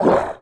monster / goblin_leafhead / damage_1.wav
damage_1.wav